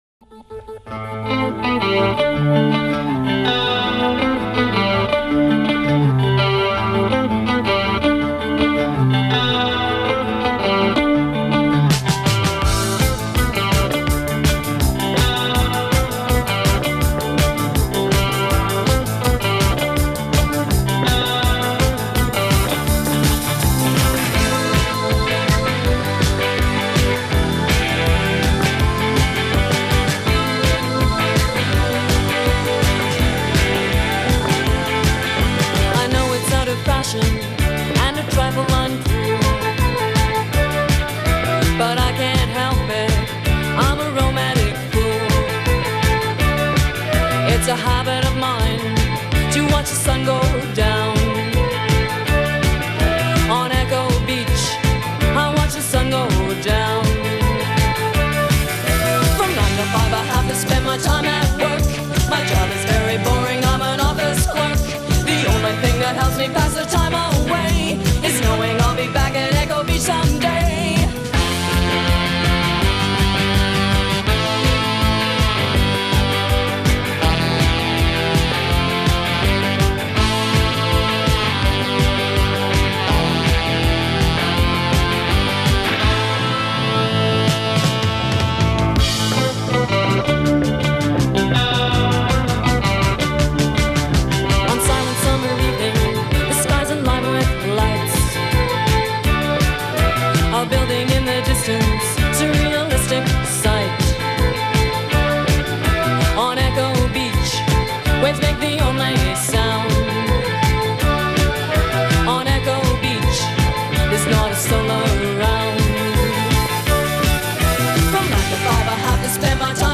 New Wave from Canada